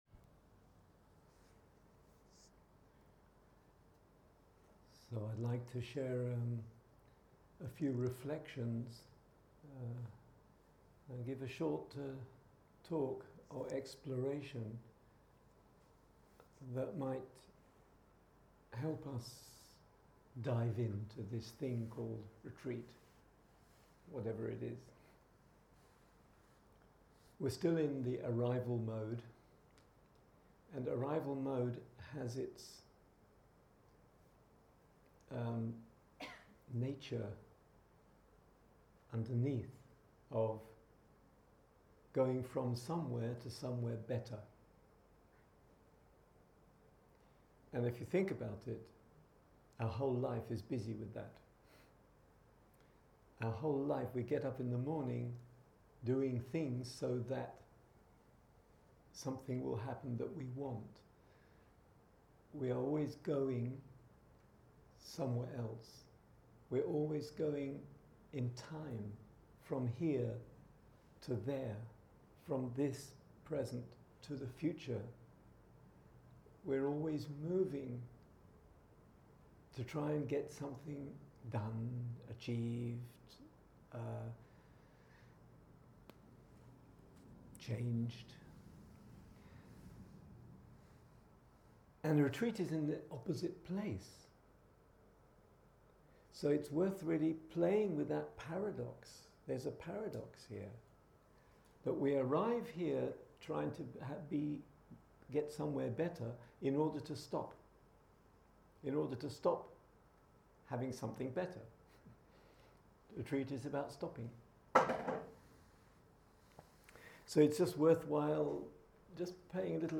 Dharma Talks